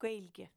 Connacht Irish Munster Irish Standard Irish Ulster Irish